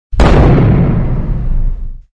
Tiếng nổ Bùm… mp3
Thể loại: Đánh nhau, vũ khí
Description: Tiếng nổ Bùm ngắn, tiếng súng nổ gọn, tiếng đoàng khô, tiếng bụp mạnh, tiếng đùng chớp nhoáng… mp3 là các sound effect phổ biến dùng để edit video. Những hiệu ứng âm thanh này tái hiện khoảnh khắc va chạm, bắn súng, nổ nhỏ, hành động nhanh với âm lượng rõ, dứt khoát và không kéo dài...
tieng-no-bum-www_tiengdong_com.mp3